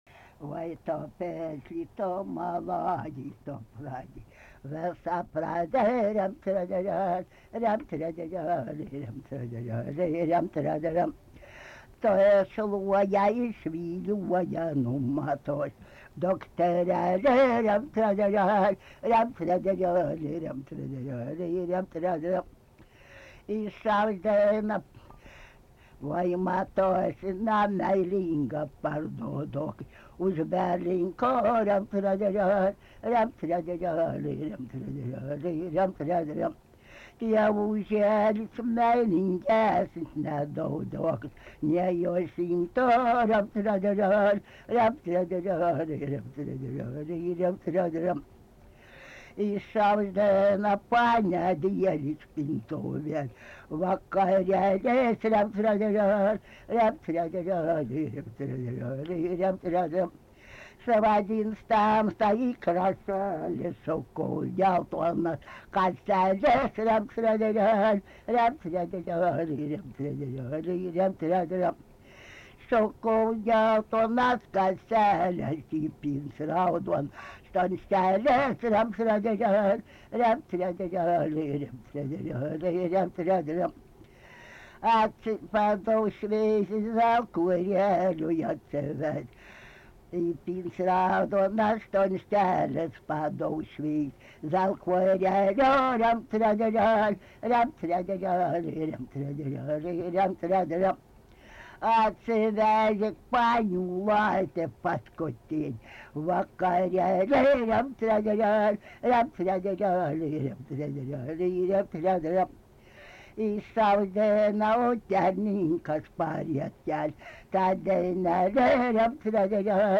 Tipas daina
Atlikimo pubūdis vokalinis
Pabaigoj fone ima lot šuo